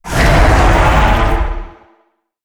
Sfx_creature_hiddencroc_alert_03.ogg